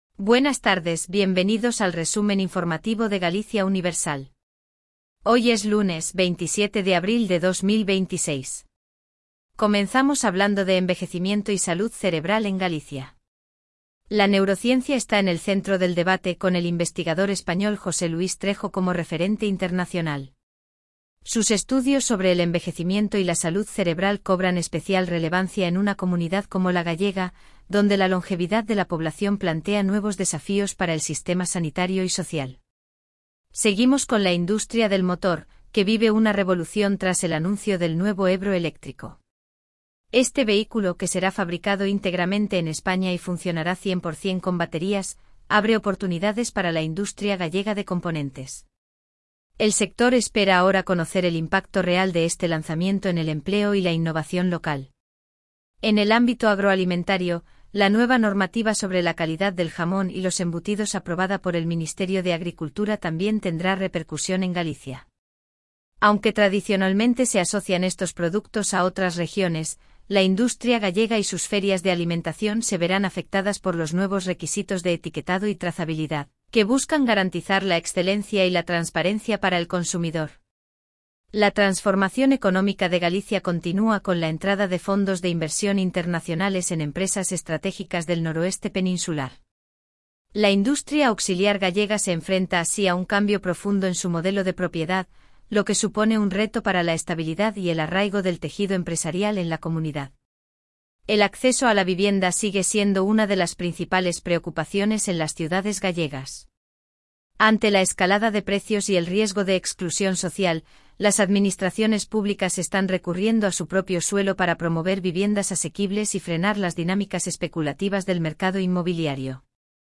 🎙 PODCAST DIARIO
Resumen informativo de Galicia Universal